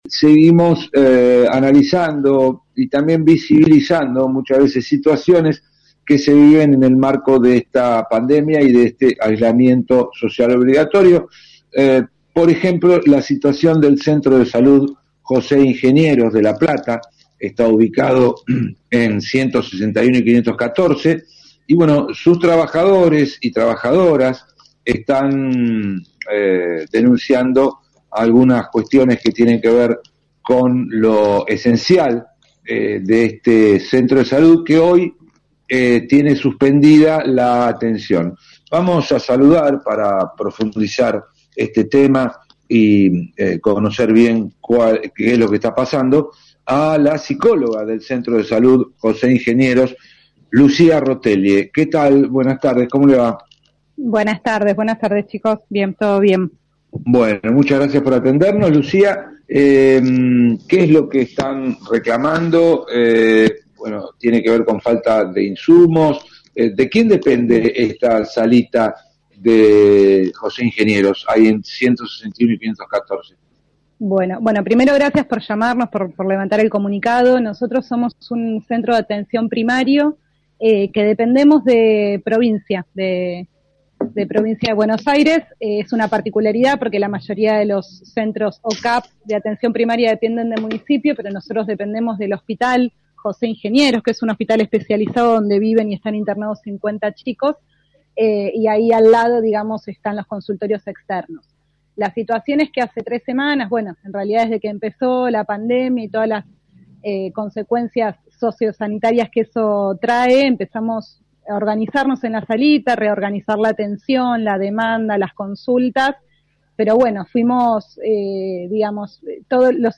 cuenta en este diálogo con radio Universidad